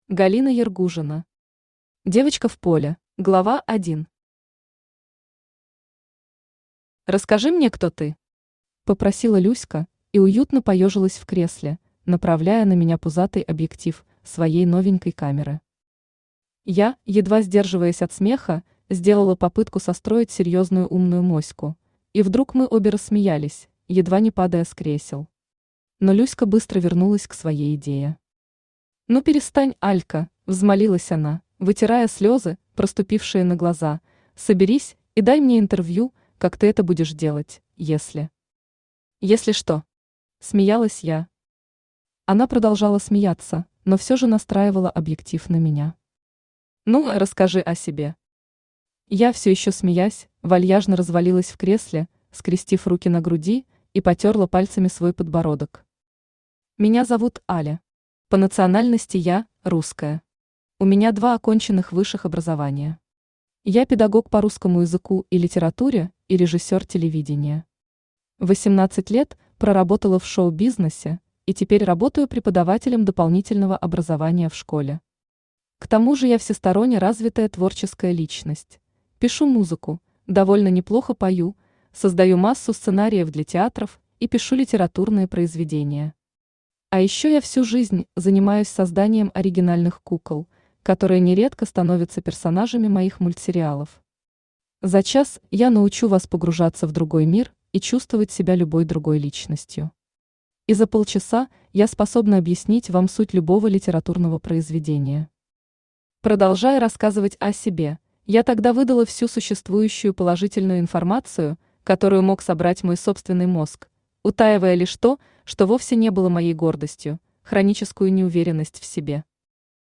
Аудиокнига Девочка в поле | Библиотека аудиокниг
Aудиокнига Девочка в поле Автор Галина Ергужина Читает аудиокнигу Авточтец ЛитРес.